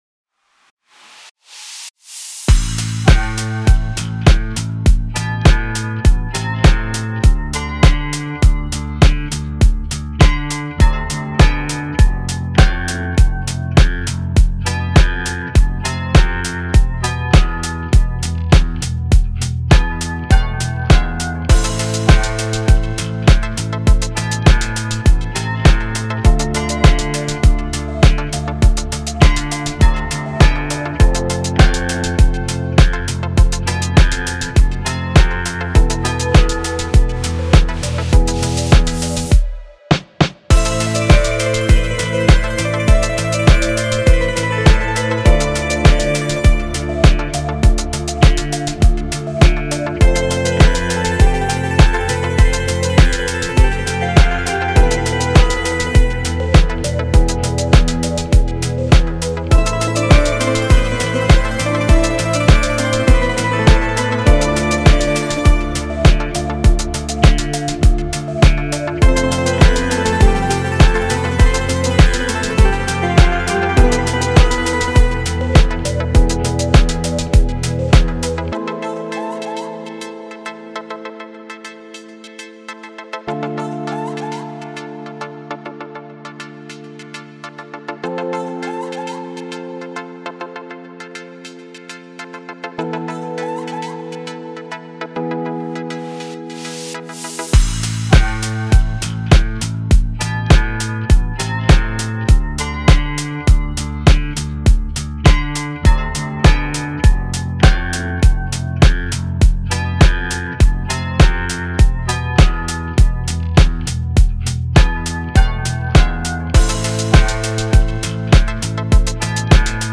موسیقی کنار تو
دیپ هاوس ریتمیک آرام موسیقی بی کلام